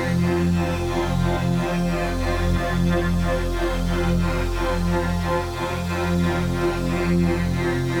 Index of /musicradar/dystopian-drone-samples/Tempo Loops/90bpm
DD_TempoDroneD_90-F.wav